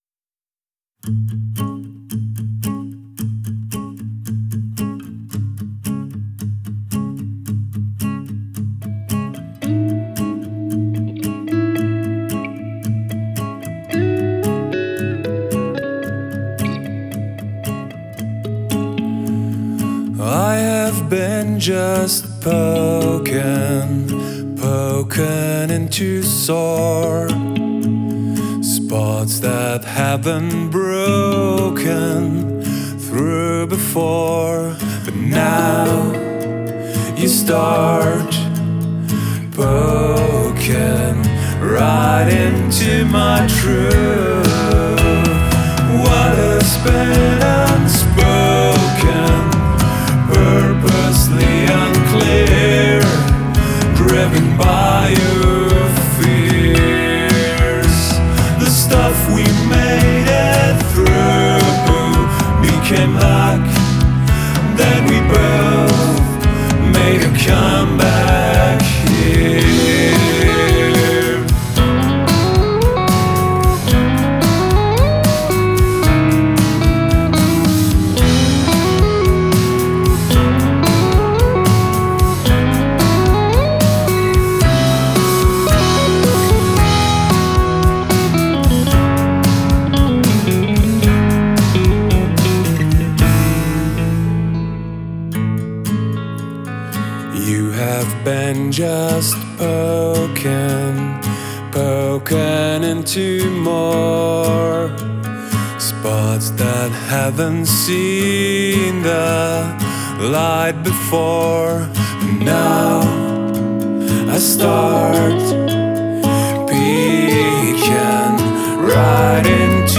on lead-guitar